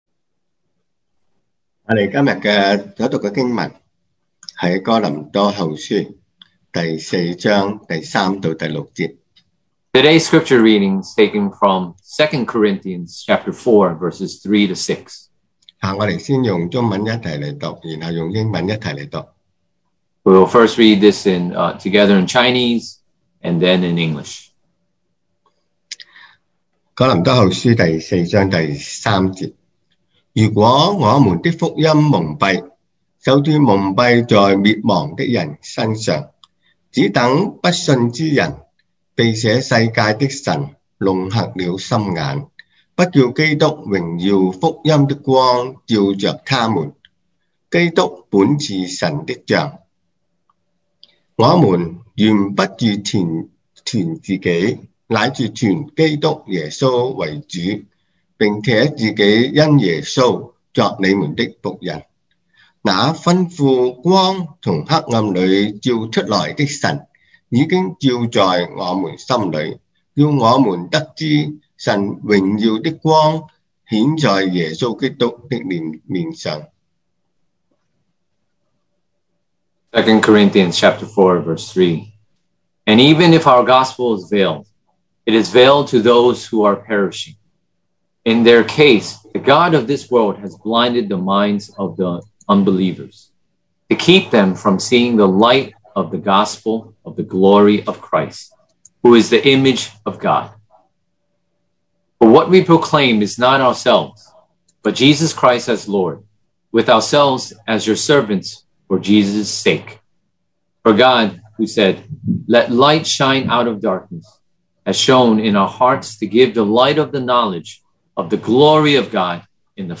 2021 sermon audios
Service Type: Sunday Morning